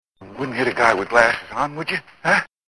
This is due to the fact that every single bloody time he uses them, you hear Jack Nicholson cackle,
More-so because the voices are really loud and hard to ignore.
batman_wouldnthitaguywithglasses.mp3